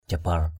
/ʥa-bar/ 1.